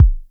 Bassdrum-35.wav